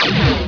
LASER2.WAV